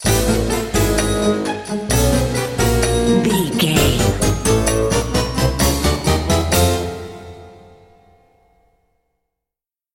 Aeolian/Minor
orchestra
percussion
silly
circus
goofy
comical
cheerful
perky
Light hearted
secretive
quirky